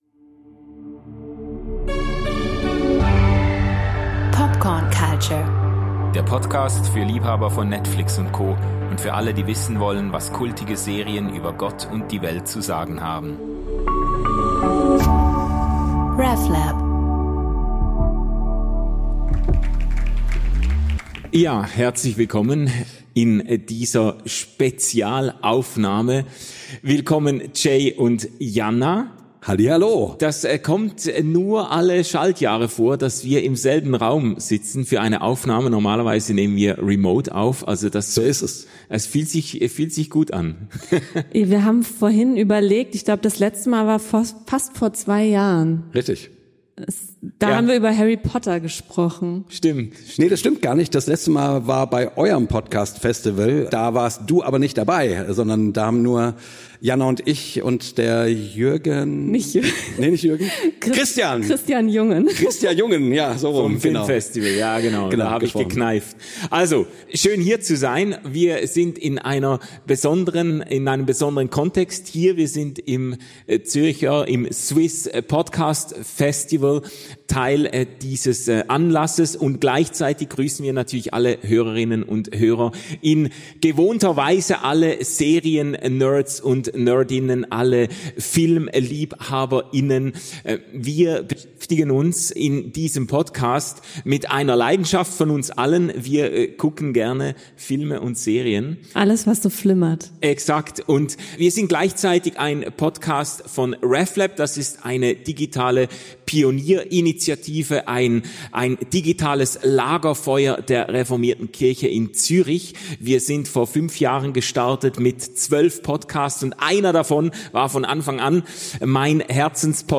In dieser Folge von Popcorn Culture, haben wir wieder eine Liveaufnahme für euch.